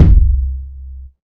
• Low Kick Sound C Key 699.wav
Royality free kick single shot tuned to the C note. Loudest frequency: 97Hz
low-kick-sound-c-key-699-wJs.wav